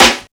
Snare (31).wav